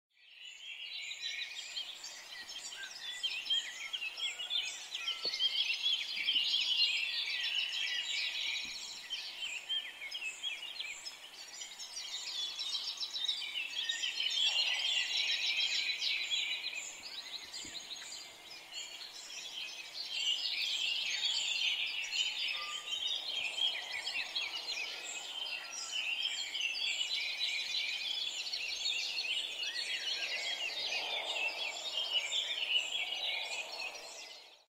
Morning Birds Singing Free Sound Effects No Copyright Free Download Hd Cp Us I Fv F Tj4 (audio/mpeg)
BIRD